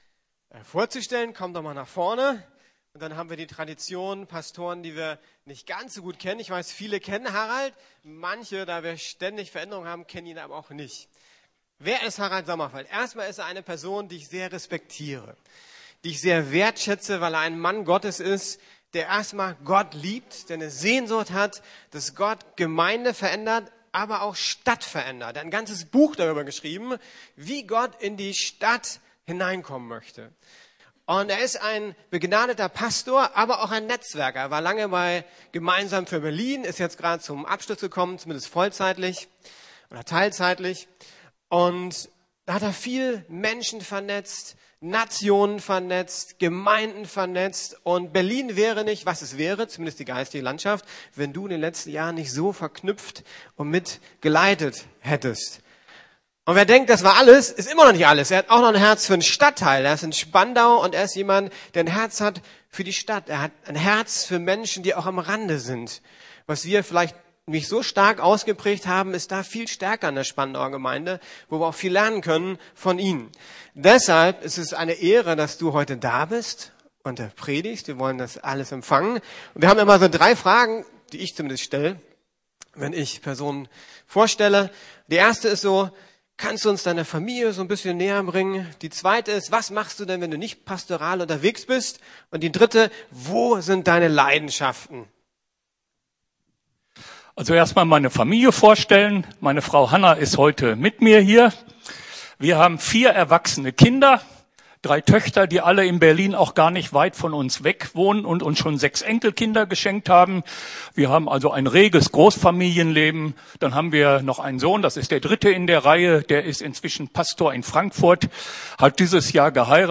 Erwachsen werden bei Wasser und Brot ~ Predigten der LUKAS GEMEINDE Podcast